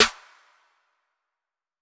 JJSnares (3).wav